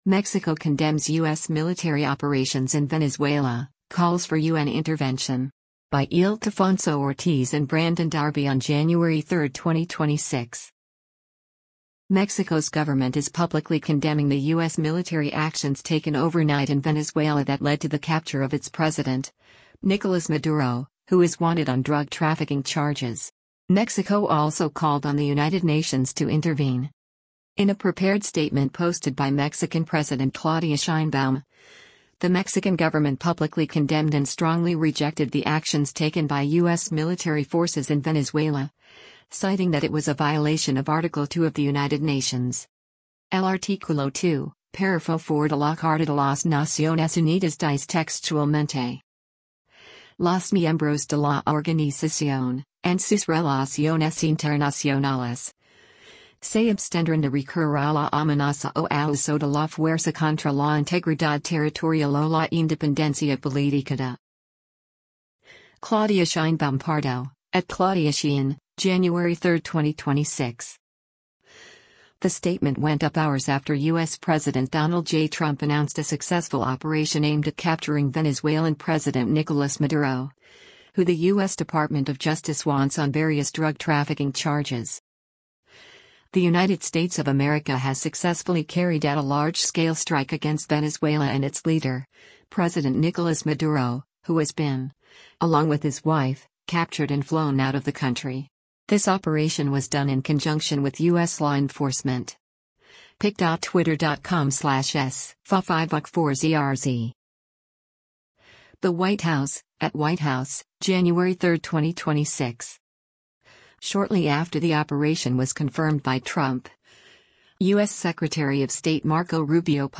Mexican President Claudia Sheinbaum takes questions during a news conference.